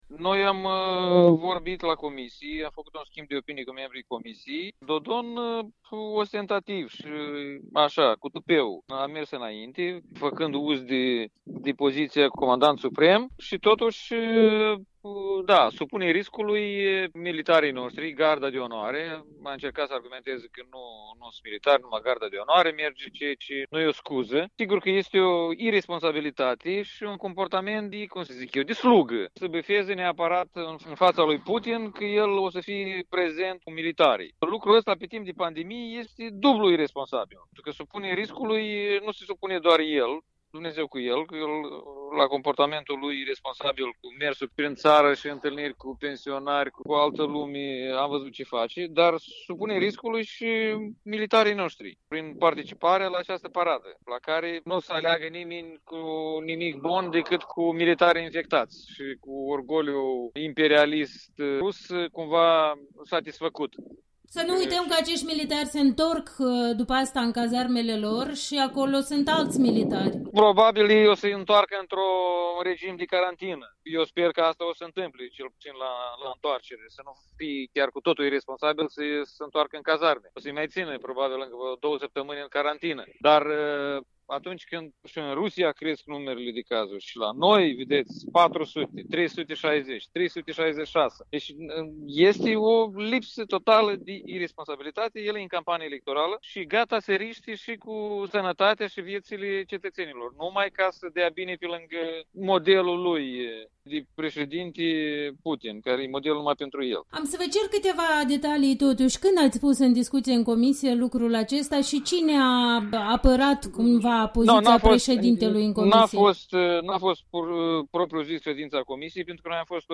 Interviu cu Igor Grosu